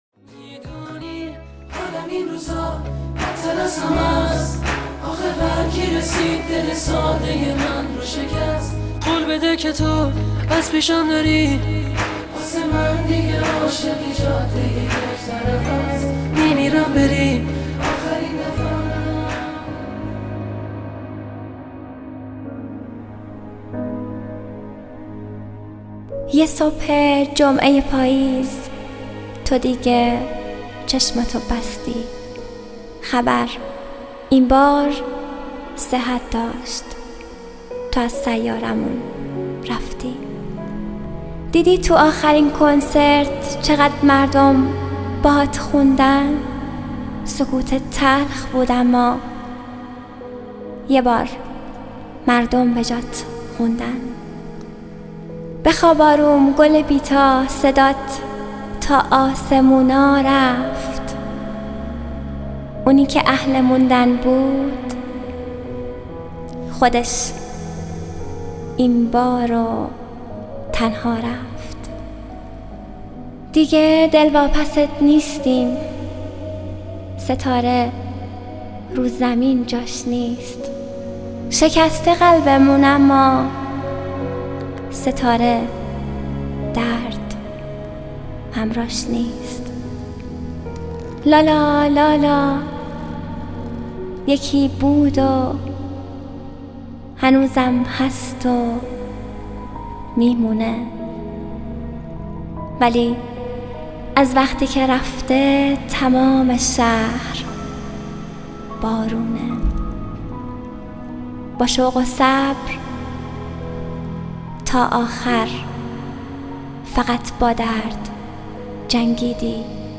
دانلود دکلمه کنسرت رویایی با صدای مریم حیدرزاده
گوینده :   [مریم حیدرزاده]